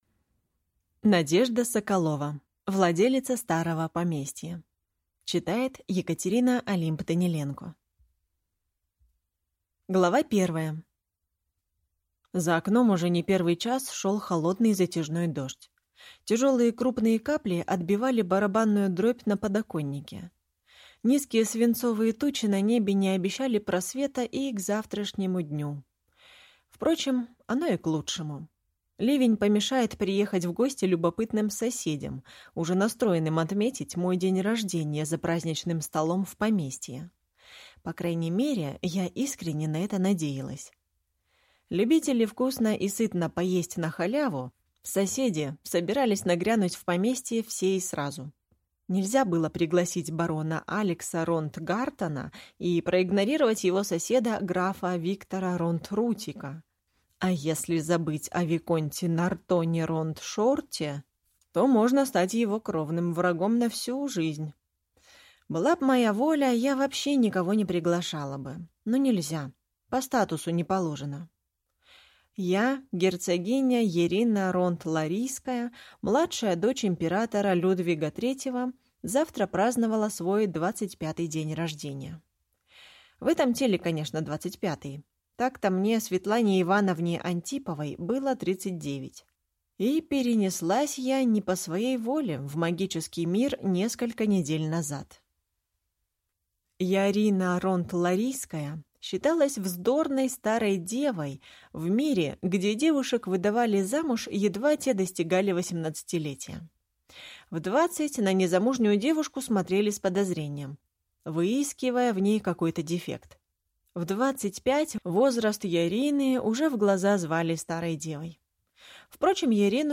Аудиокнига Владелица старого поместья | Библиотека аудиокниг